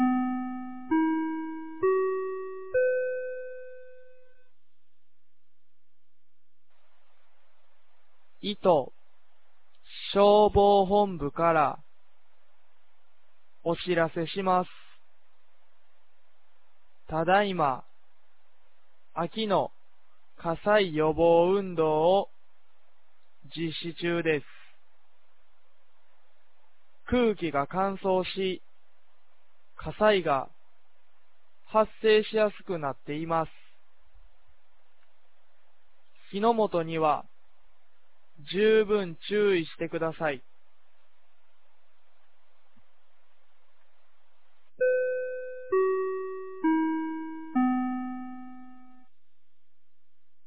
2023年11月13日 10時01分に、九度山町より全地区へ放送がありました。
放送音声